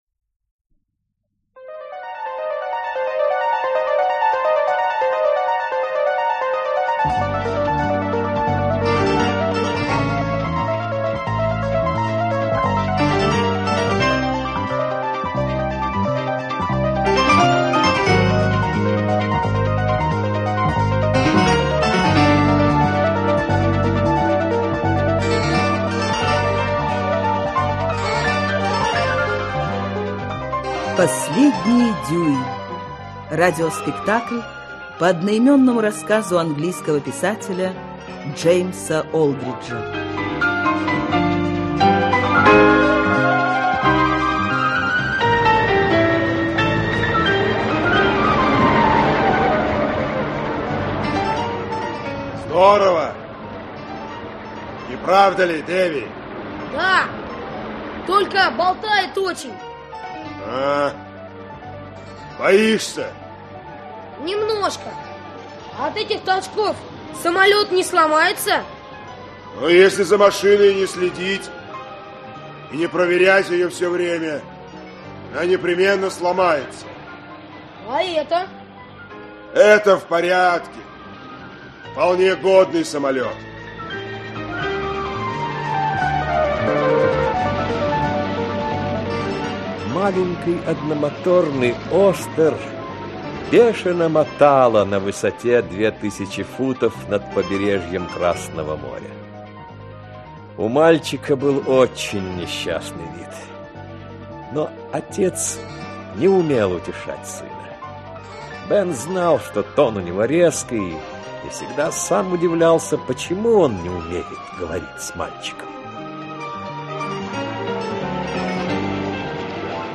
Аудиокнига Последний дюйм | Библиотека аудиокниг
Aудиокнига Последний дюйм Автор Джеймс Олдридж Читает аудиокнигу Актерский коллектив.